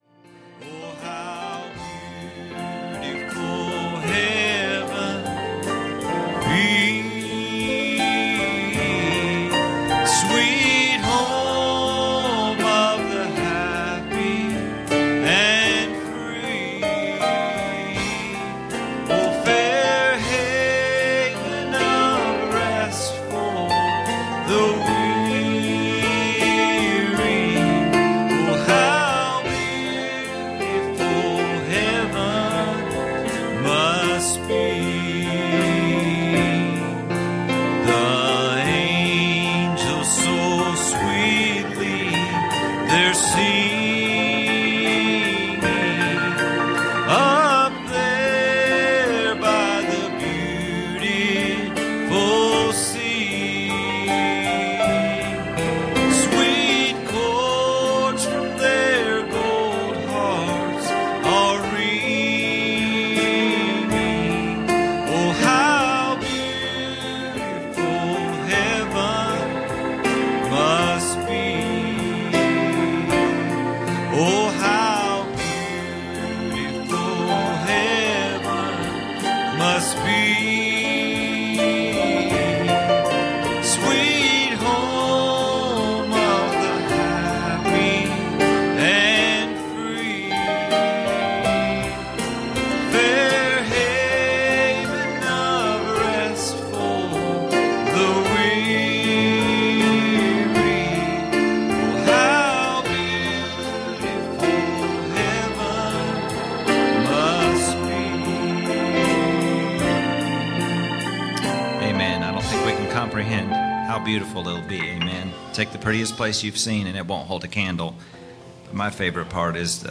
Passage: Matthew 6:14 Service Type: Sunday Morning